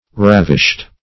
Ravish \Rav"ish\ (r[a^]v"[i^]sh), v. t. [imp. & p. p. Ravished
ravished.mp3